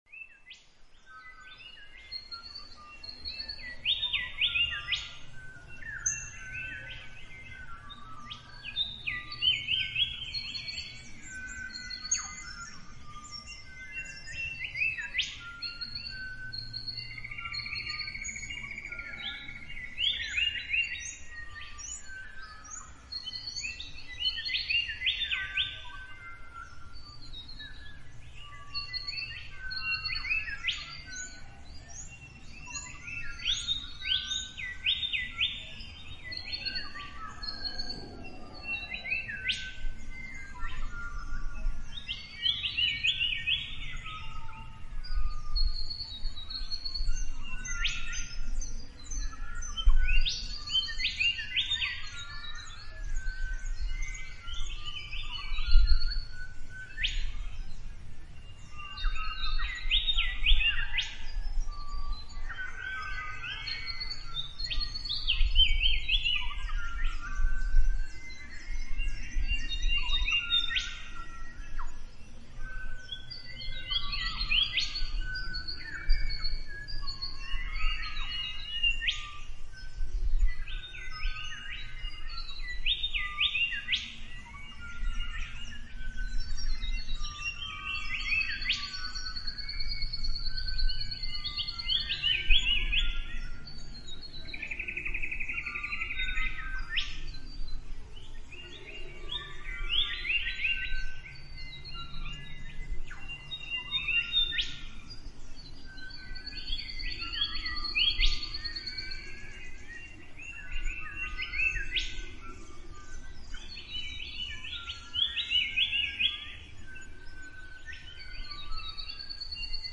Bande sonore
Bande sonore sur le chant des oiseaux pour vous plonger dans ces poèmes.
2-oiseau.mp3